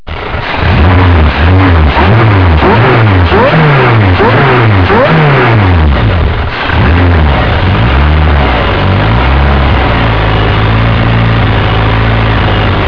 Suono motore clio con volumetrico Clicca per ascoltare una riproduzione del suono di questo motore con volumetrico ProtoXide